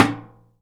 metal_tin_impacts_soft_06.wav